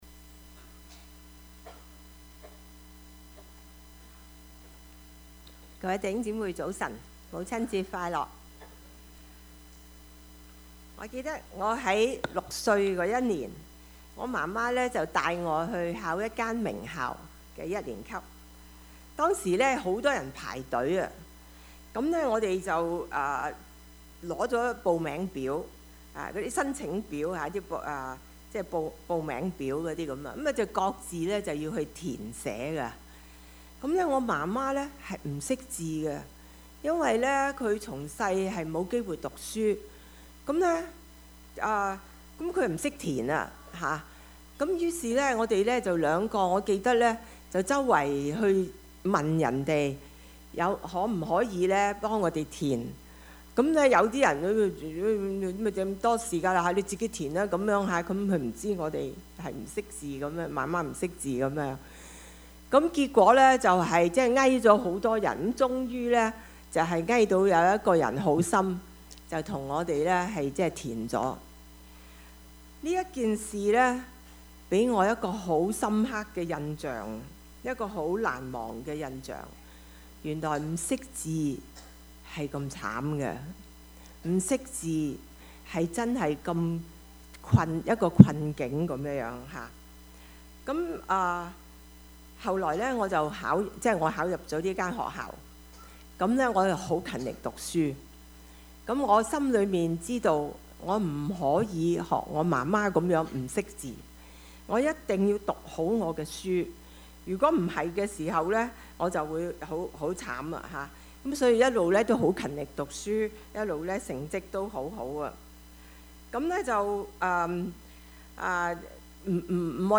Service Type: 主日崇拜
Topics: 主日證道 « 你們要彼此相愛 你們要休息 »